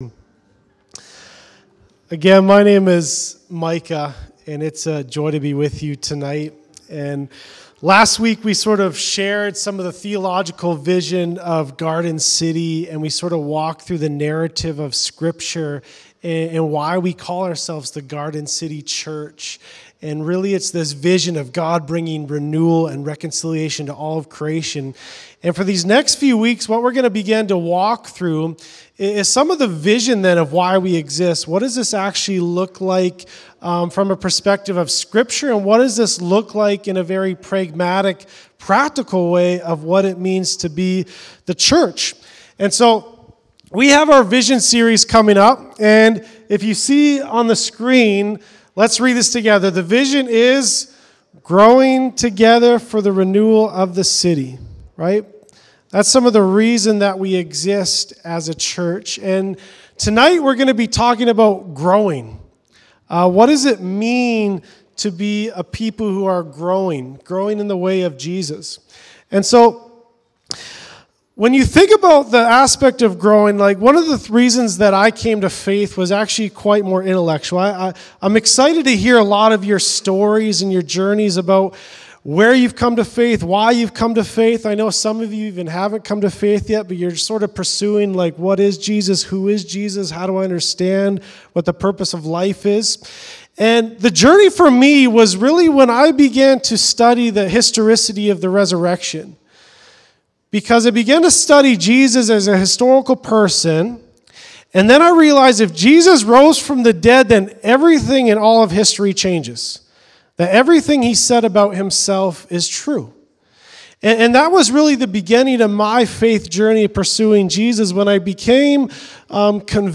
Growing as Garden City Church The first sermon in our Vision Series exploring what it means to grow by practicing the way of Jesus.